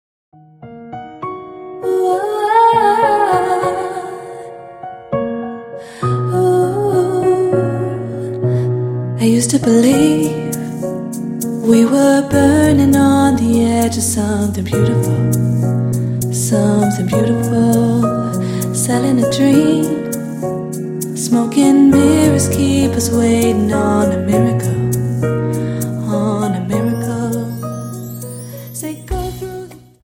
Rumba 25 Song